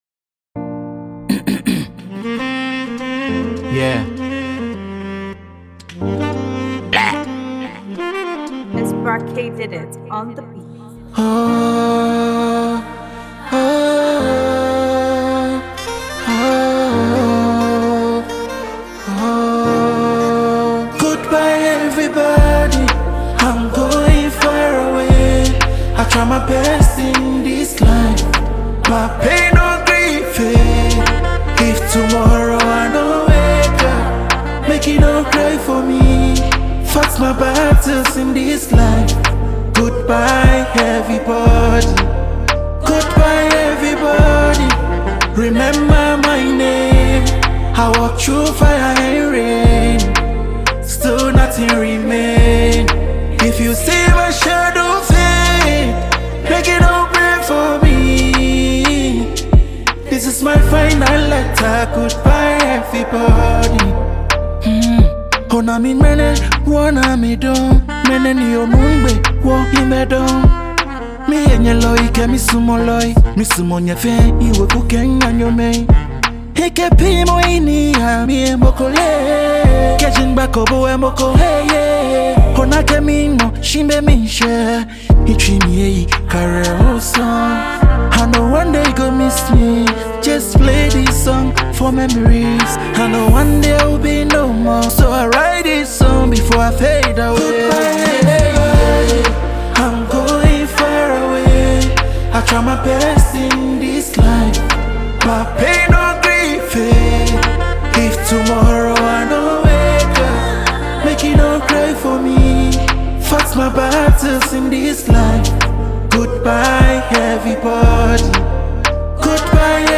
blending expressive vocals with a smooth Afrobeat rhythm.
Genre: Afrobeats